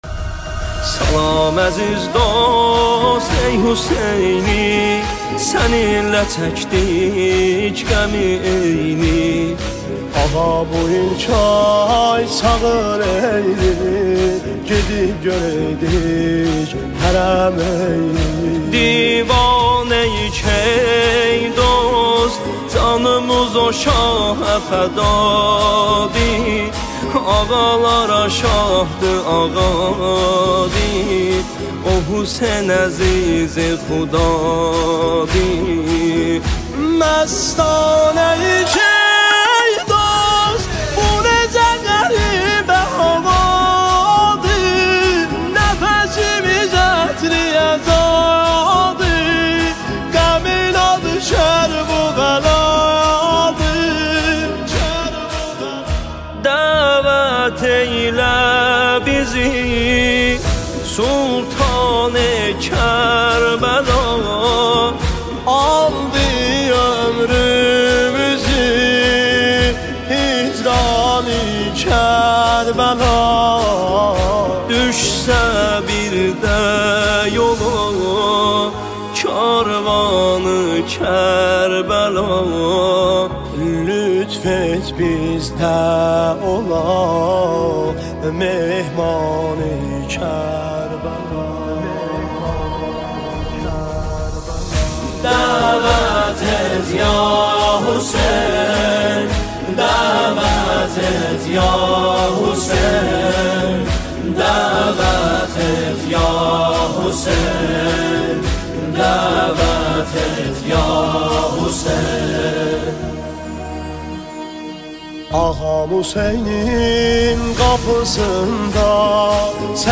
نماهنگ ترکی